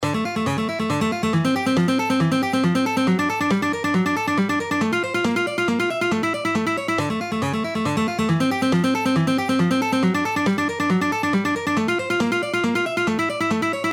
Alternate Picking Exercises 2
Exercise 2 in original speed:
Alternate-Picking-Exercises-2-1.mp3